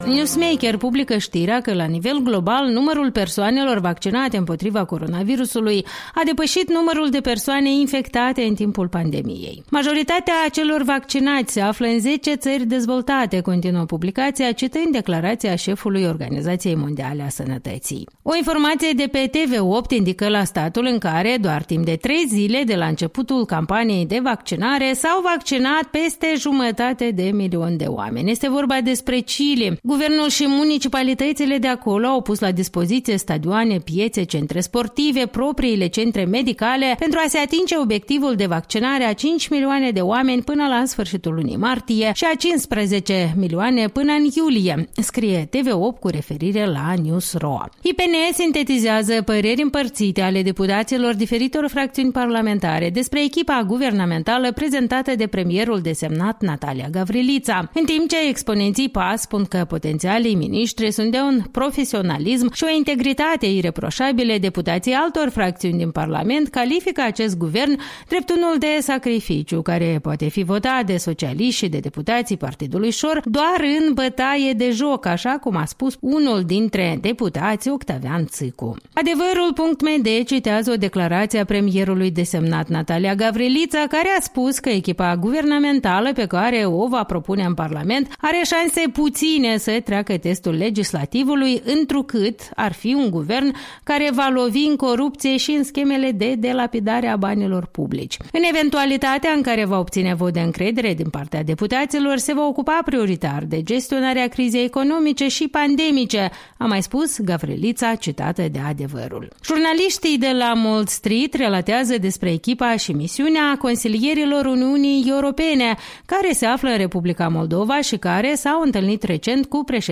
Revista matinală a presei, la radio Europa Liberă.